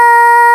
Index of /m8-backup/M8/Samples/Fairlight CMI/IIX/CHORAL
TENOR2.WAV